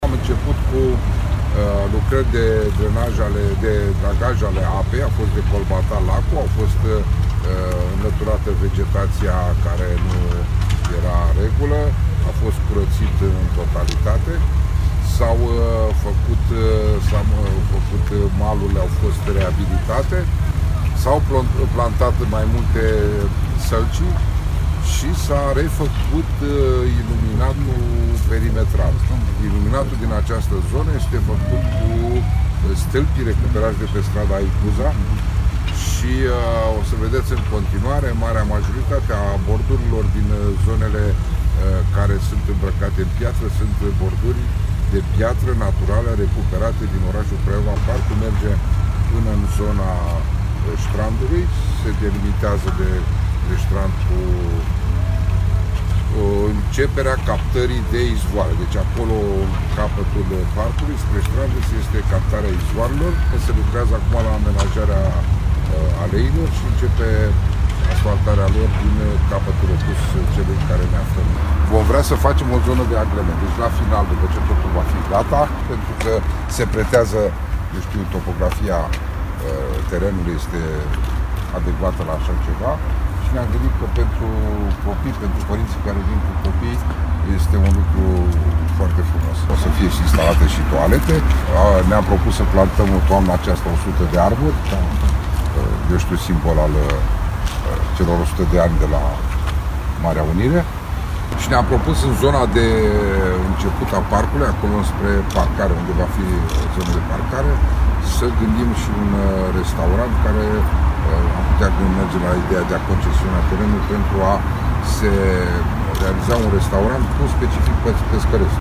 La cateva luni de la demararea lucrarilor de reamenajare, astazi, primarul Craiovei, Mihail Genoiu a realizat o vizita de lucru in Parcul Hanul Doctorului si a explicat care sunt planurile pentru zona de agrement.